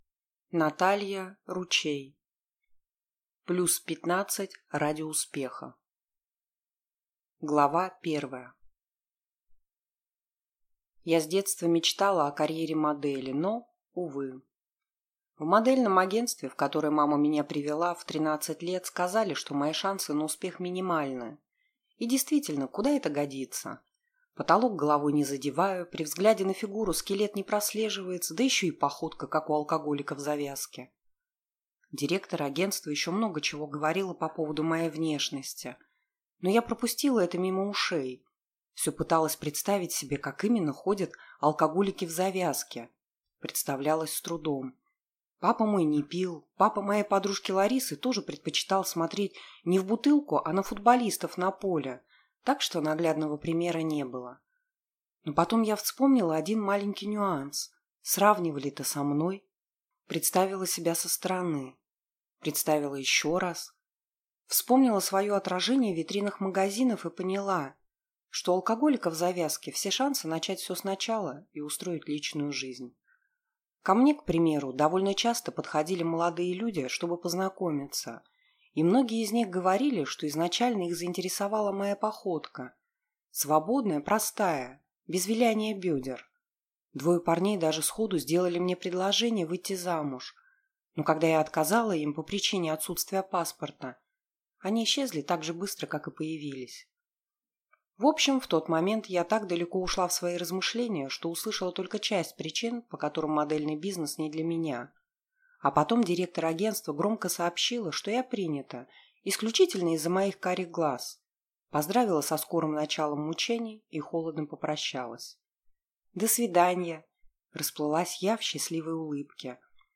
Аудиокнига Плюс пятнадцать ради успеха | Библиотека аудиокниг